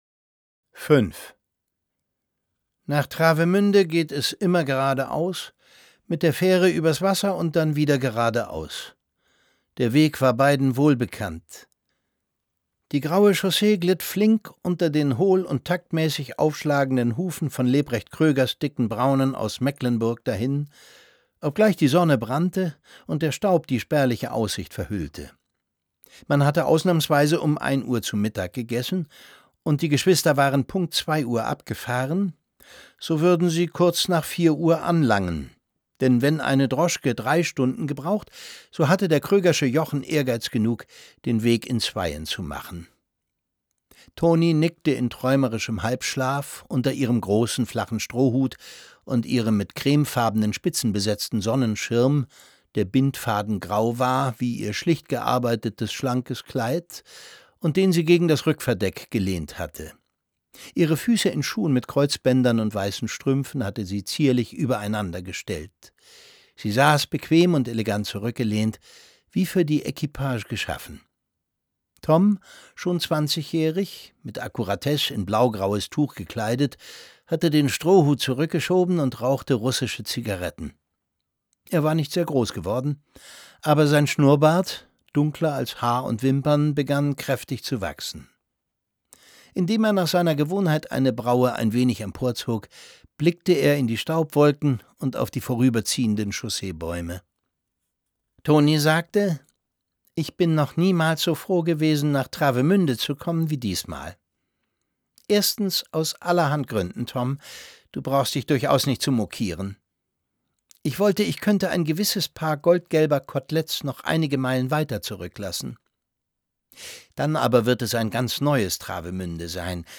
Sie magert ab und verliert ihre Lebensfreude – was ihren Vater, Konsul Buddenbrook, veranlasst, Tony für die Sommermonate ans Meer zu schicken, nach Travemünde ins Haus von Lotsenkommandant Schwarzkopf. Es liest Thomas Sarbacher.